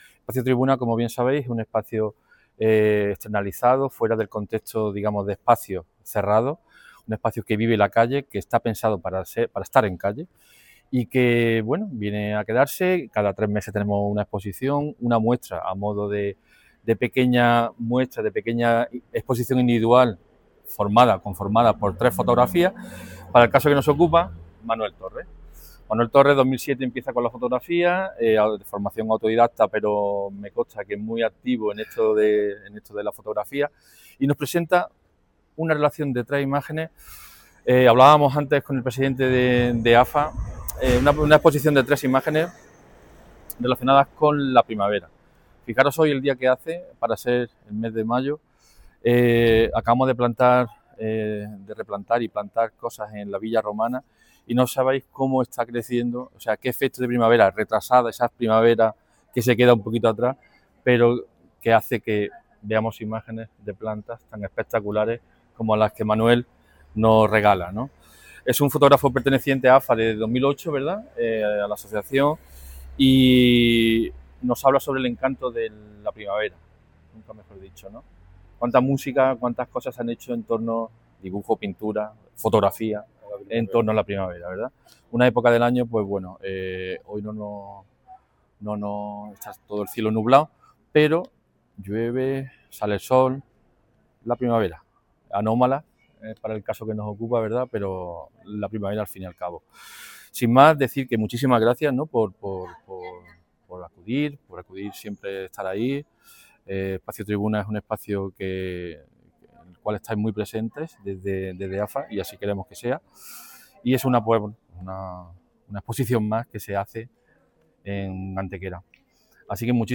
Durante la presentación, el concejal de Cultura, José Medina Galeote, destacó el valor de esta iniciativa: “Espacio Tribuna es un proyecto pensado para vivir la calle, una ventana cultural que se abre cada tres meses con pequeñas exposiciones individuales, que invitan a pararse y observar”.
Cortes de voz